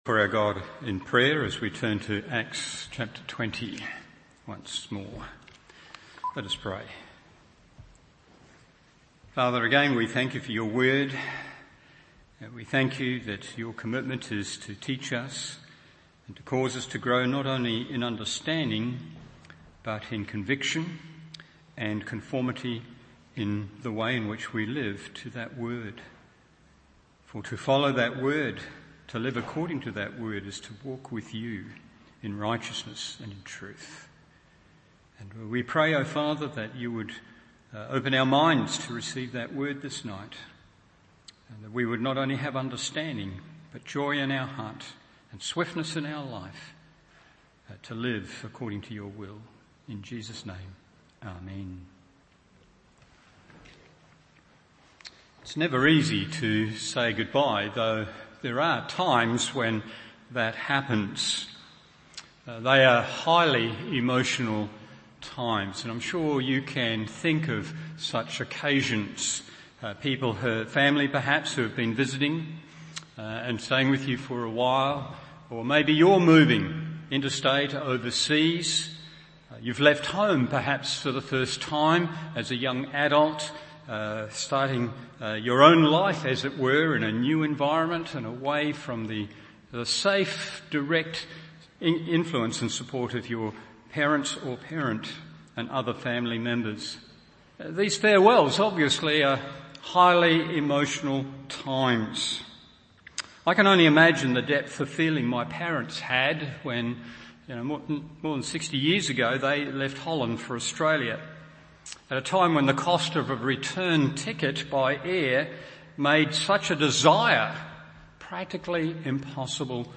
Evening Service Acts 20:17-27 1.